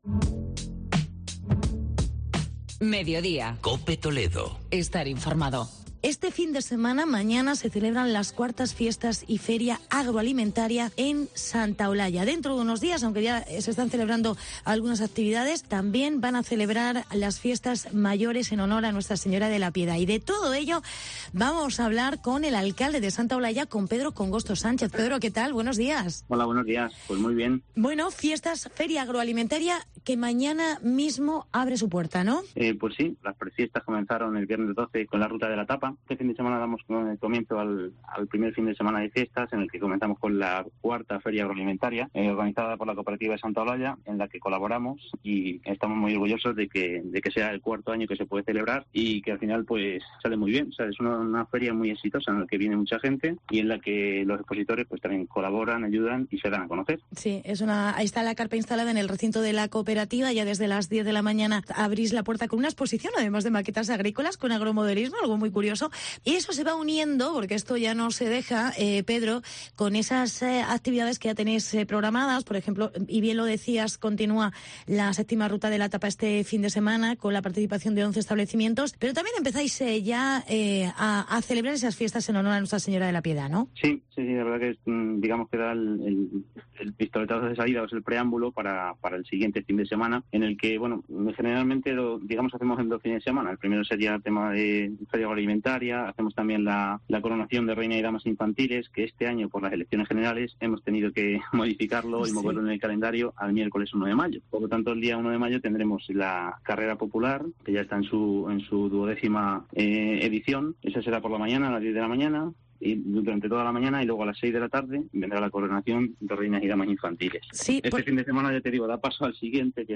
Con la IV Feria Agroalimentaria comienzan las Fiestas de Santa Olalla. Entrevista al alcalde Pedro Congosto